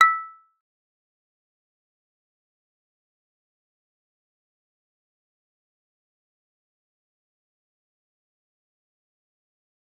G_Kalimba-E6-f.wav